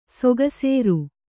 ♪ sogasēru